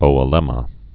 (ōə-lĕmə)